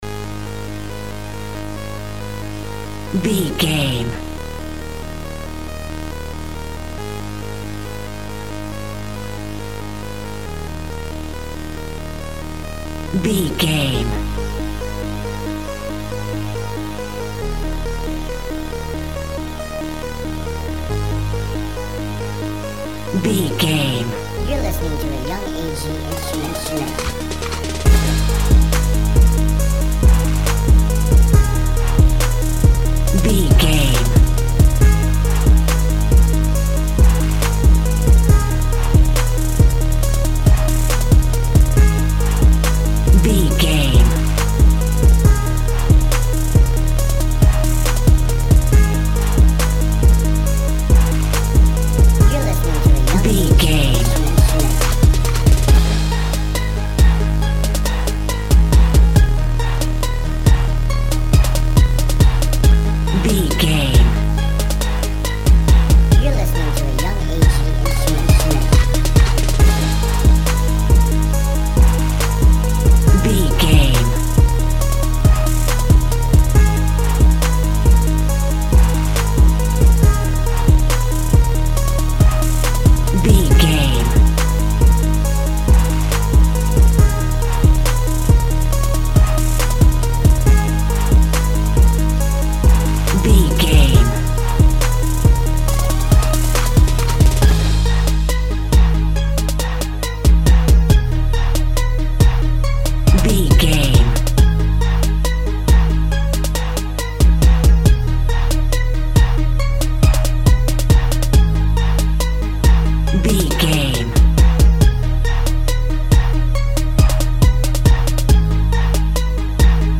Aeolian/Minor
G#
hip hop
chilled
laid back
groove
hip hop drums
hip hop synths
piano
hip hop pads